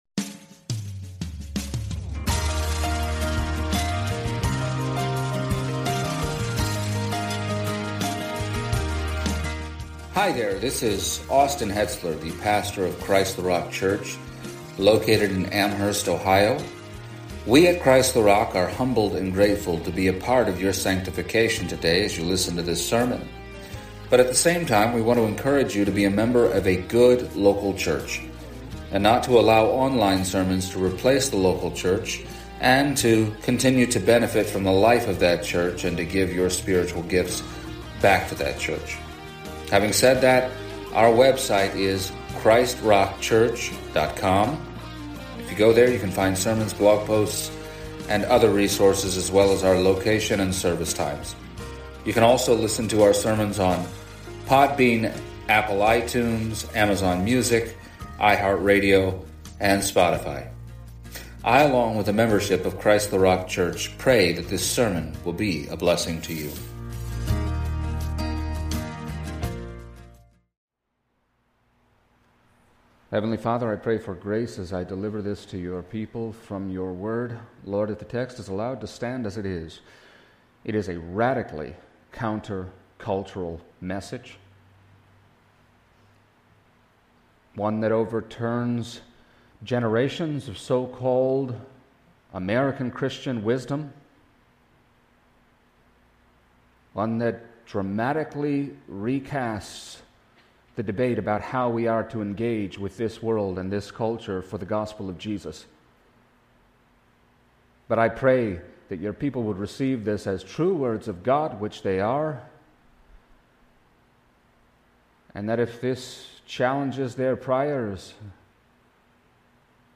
Passage: Acts 19:11-20 Service Type: Sunday Morning